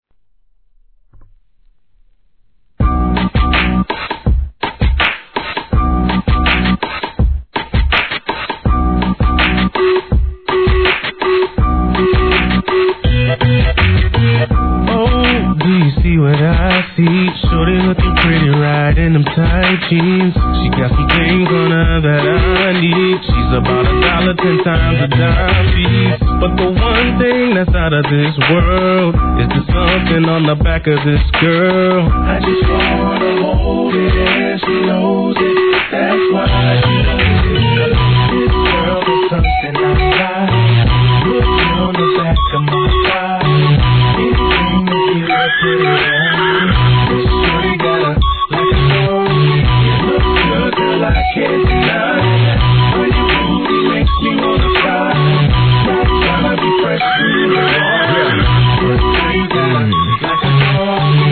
HIP HOP/R&B
四人組ヴォーカルグループ!!